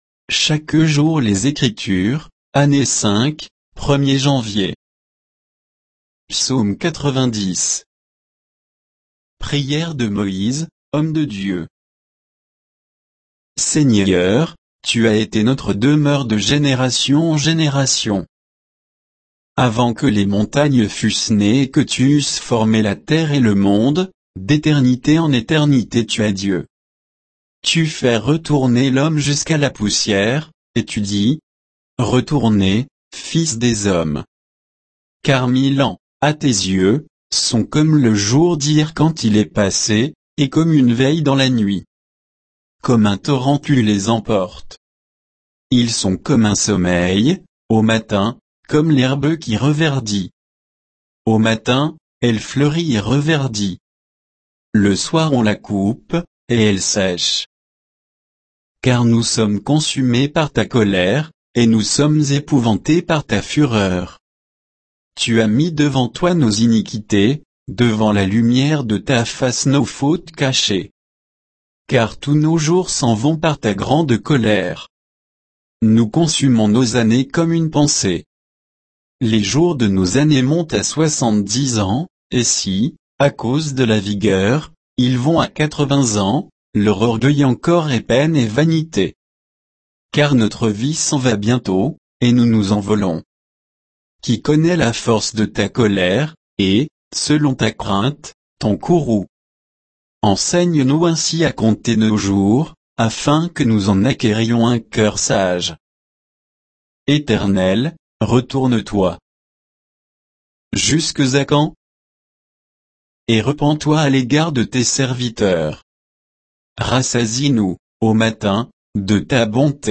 Méditation quoditienne de Chaque jour les Écritures sur Psaume 90